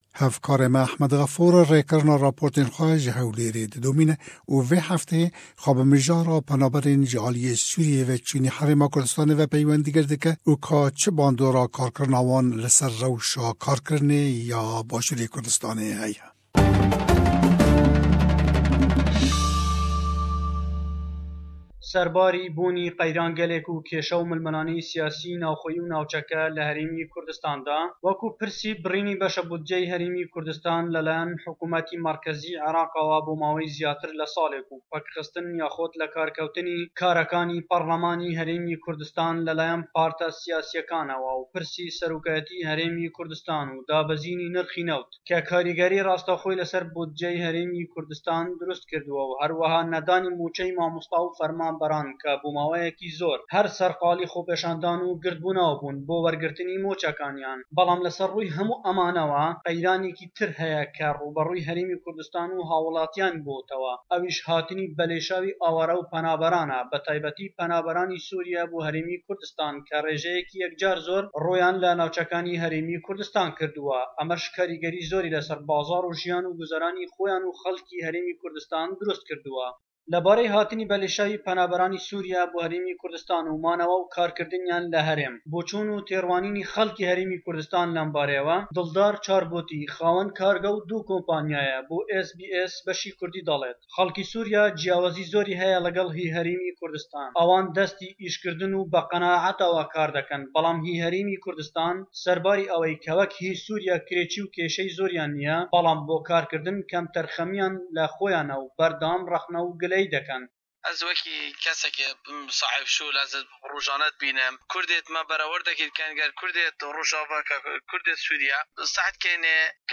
rêkirina raportên xwe ji Hewlêrê didomîne